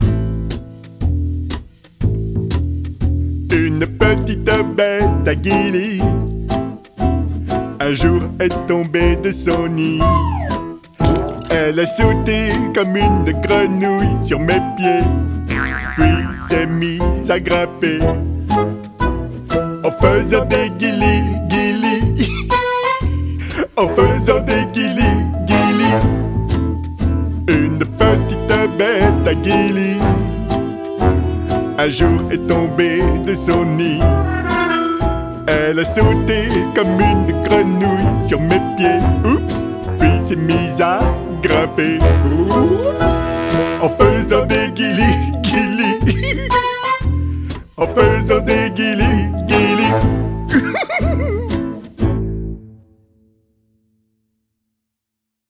comptines